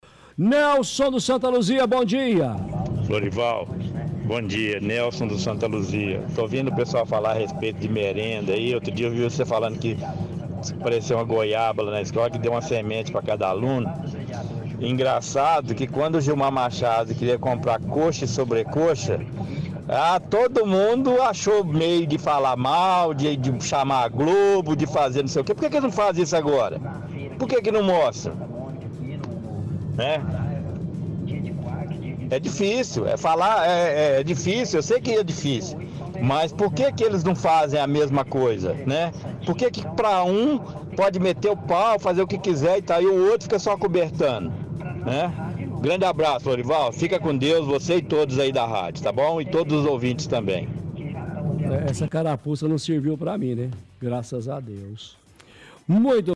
– Ouvinte fala que na época do Gilmar machado quando quis comprar coxa e sobrecoxa para a merenda escolar, muitos criticaram, mas que agora ninguém faz nada.